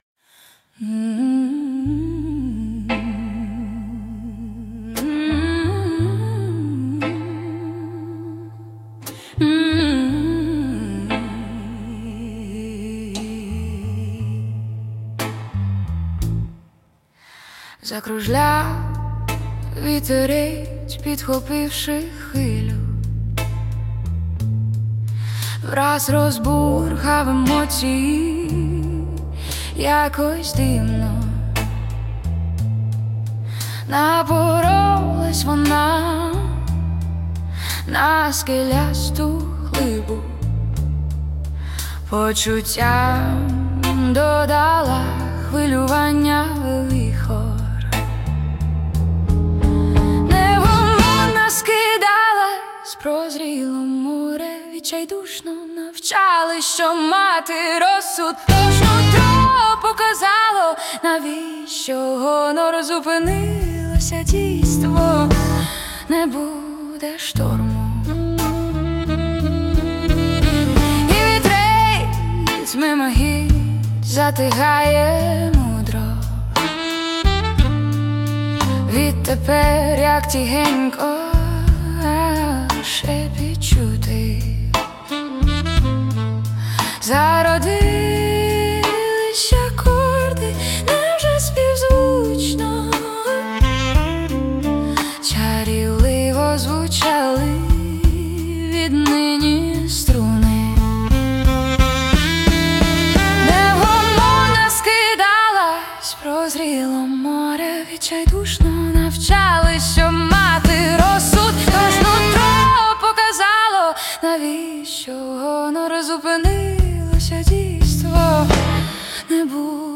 Музична композиція створена за допомогою SUNO AI
12 12 16 Чудові слова! 021 А музичний початок п'янкий...а згодом,як пробудження!
Проникливо, мелодійно.